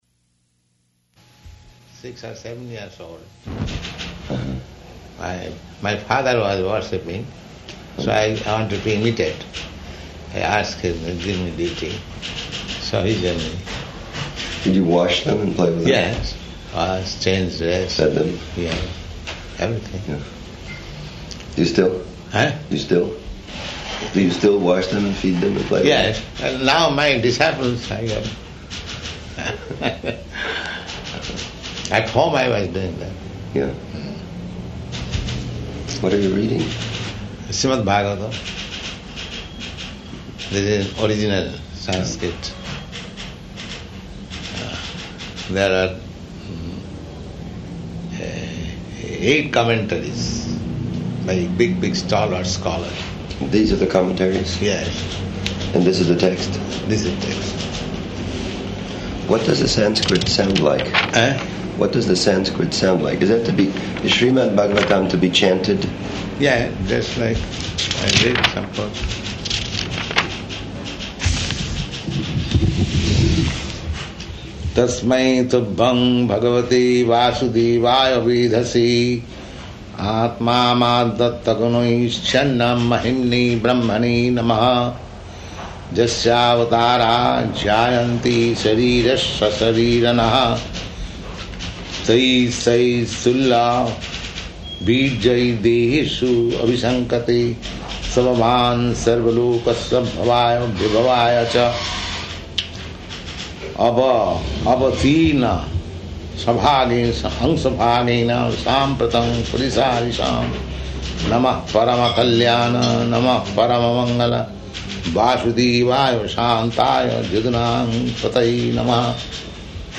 Room Conversation with Allen Ginsberg
Type: Conversation
Location: Colombus